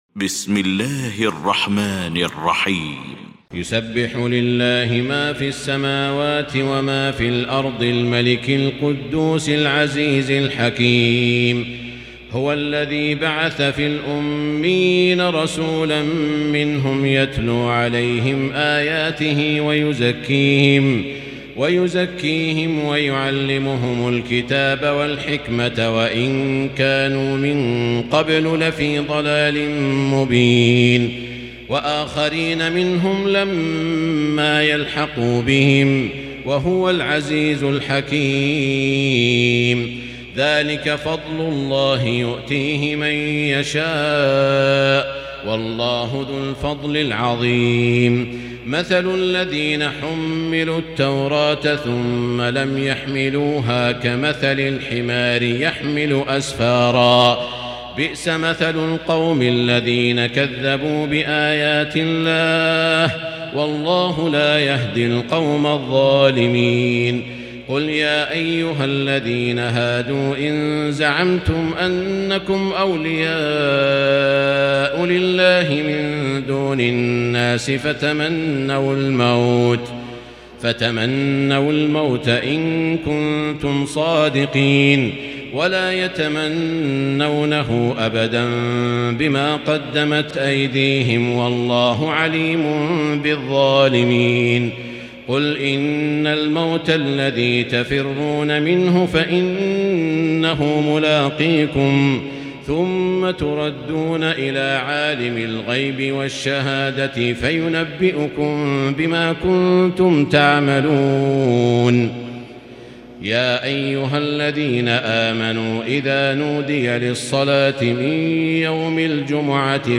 المكان: المسجد الحرام الشيخ: فضيلة الشيخ عبدالله الجهني فضيلة الشيخ عبدالله الجهني الجمعة